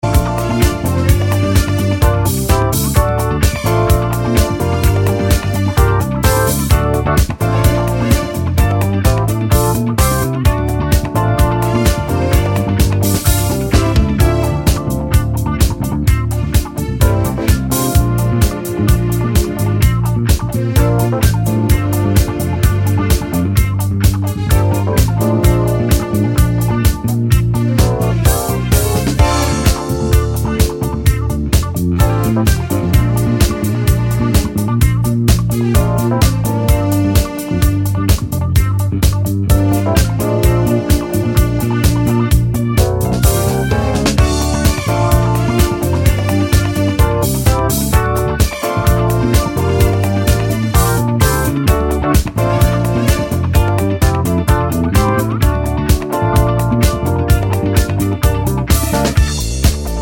no Backing Vocals Dance 3:49 Buy £1.50